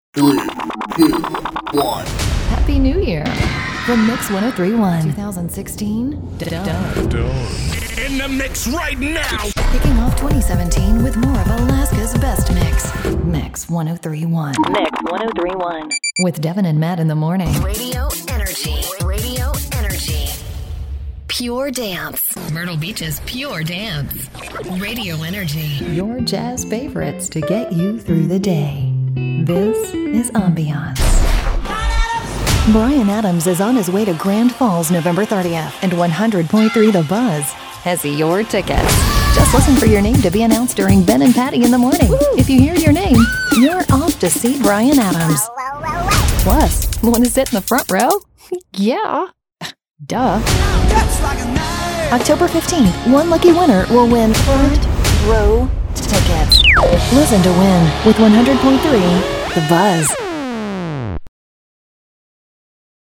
Female Voice Over, Dan Wachs Talent Agency.
Warm, Authoritative, Spokesperson.
Radio Imaging